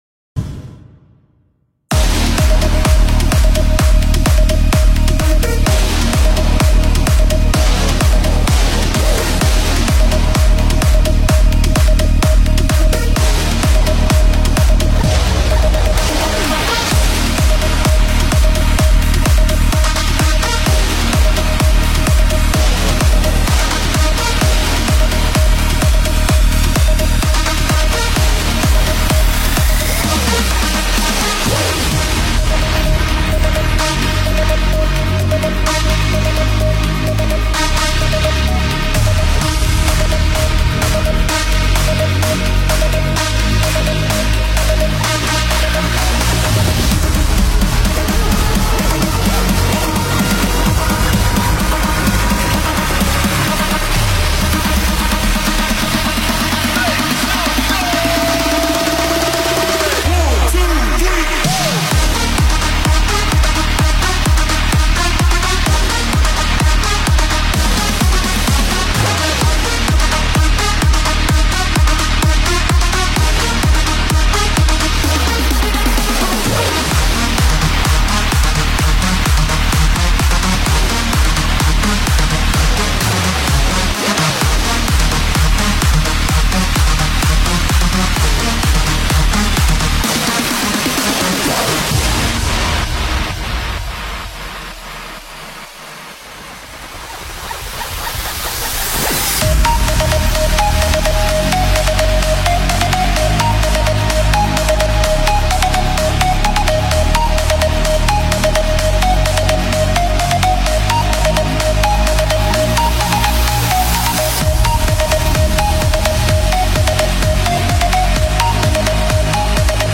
BigroomFL Studio工程音乐舞曲工程